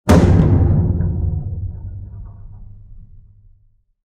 Heavy-metal-staircase-stomp-sound-effect.mp3